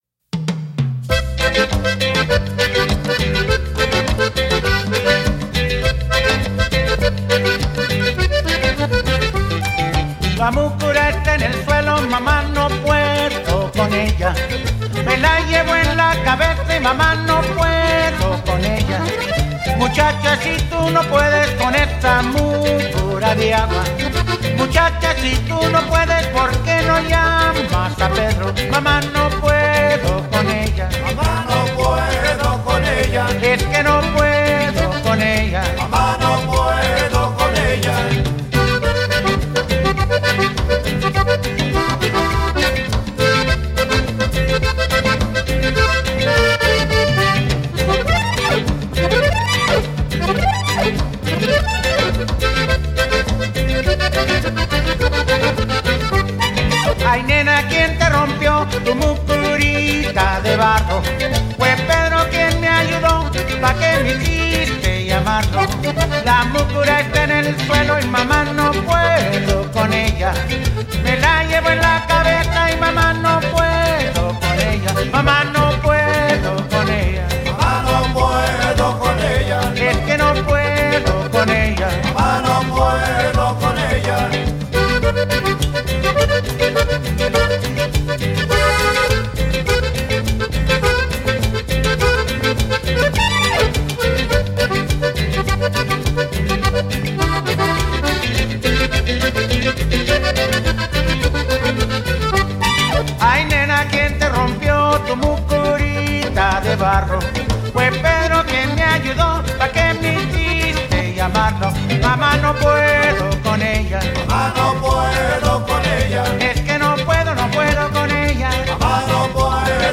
an unabating party atmosphere pervades.